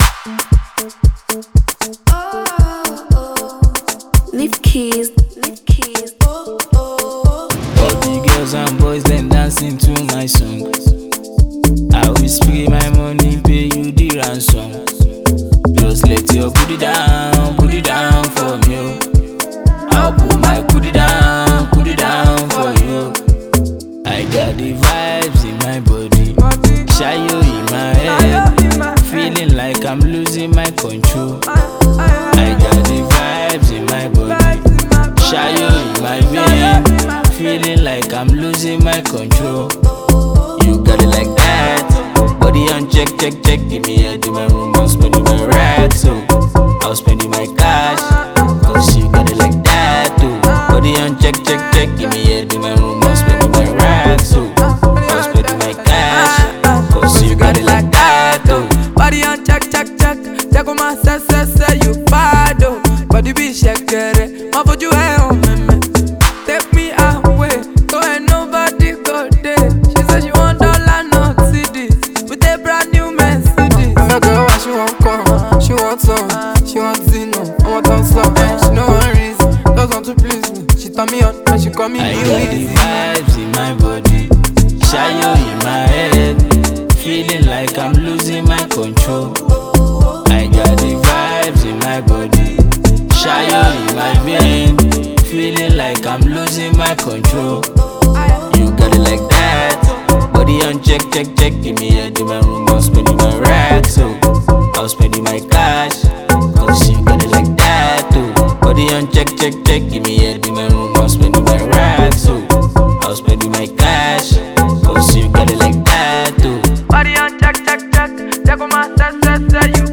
a Nigerian Afrobeat singer